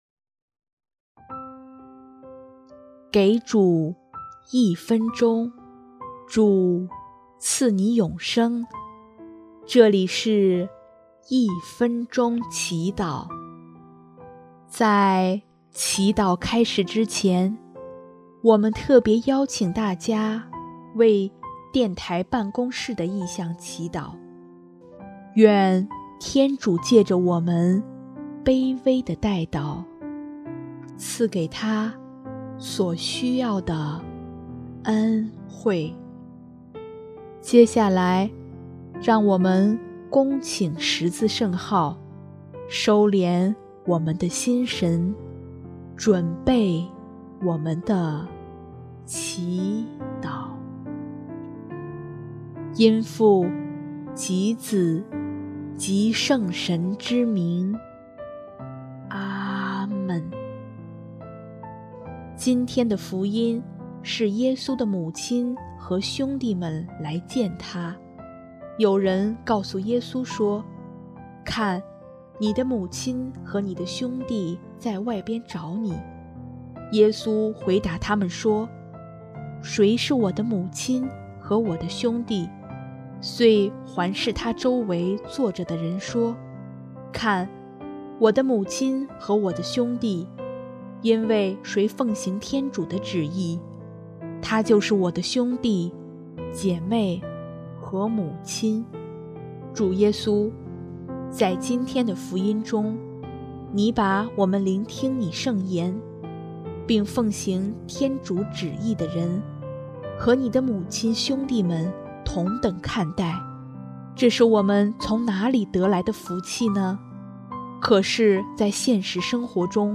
音乐：第二届华语圣歌大赛参赛歌曲《一生寻求祢》（电台办公室：求主帮助圣歌创作大赛的顺利推行）